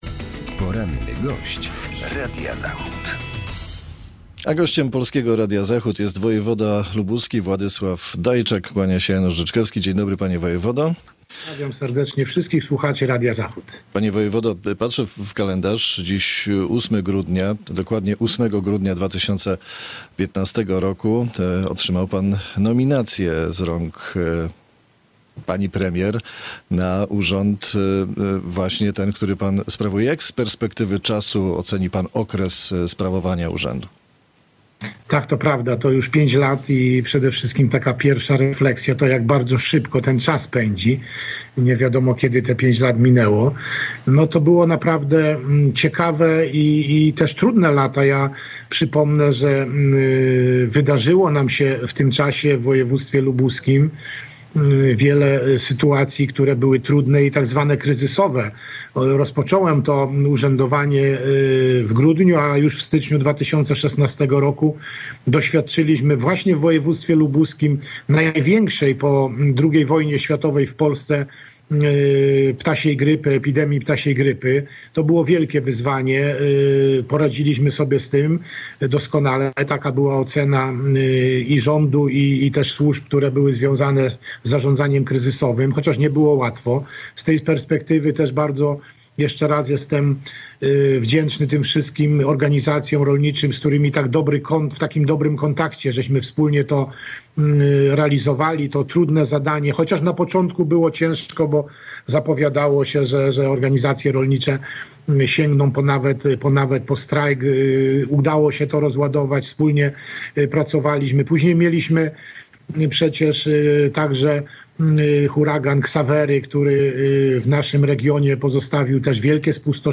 Dziś rano gościem Radia Zachód był Wojewoda Władysław Dajczak.